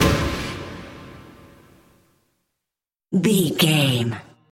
Thriller
Aeolian/Minor
strings
drums
cello
violin
percussion
ominous
dark
suspense
haunting
tense
creepy